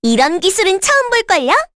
Rehartna-Vox_Skill5_kr_b.wav